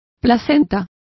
Complete with pronunciation of the translation of afterbirths.